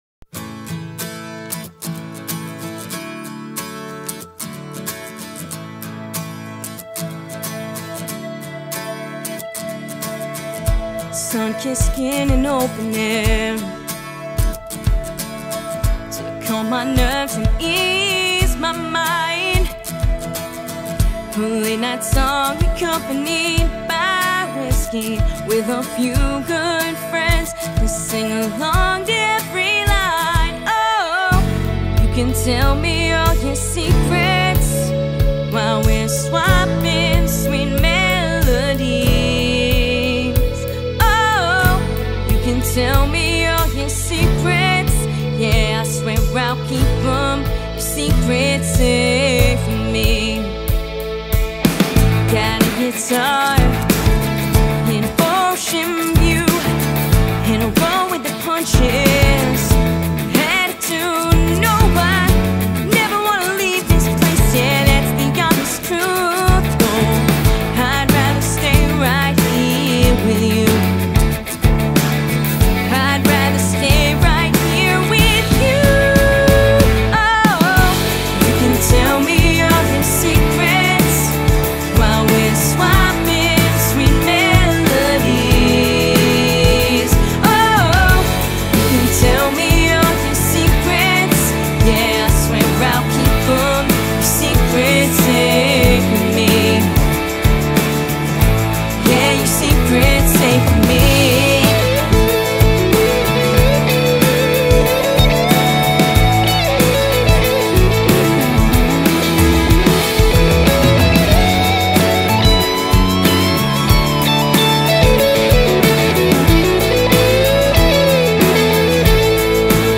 Vocals, Acoustic Guitar
Electric Guitar
Bass, Keys, Drums